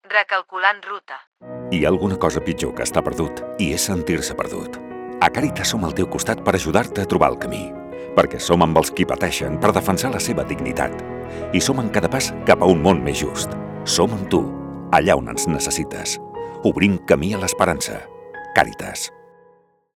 Cunya Ràdio